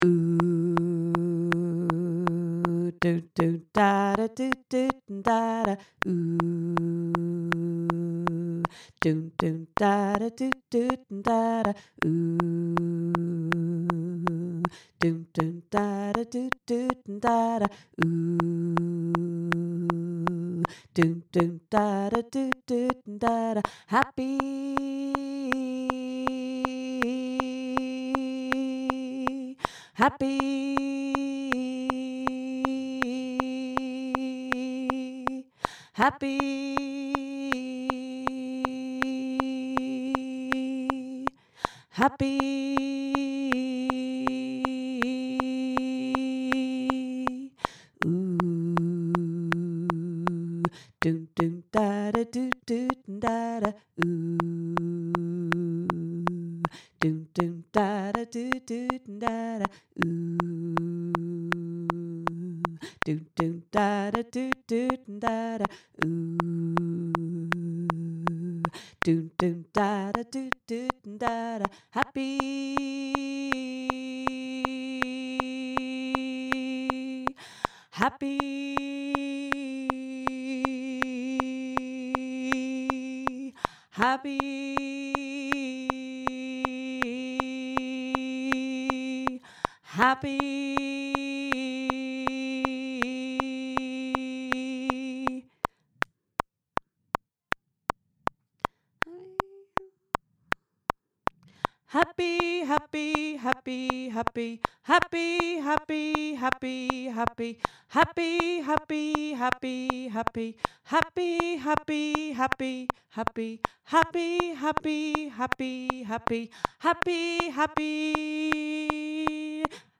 happy-breakaway-tenor.mp3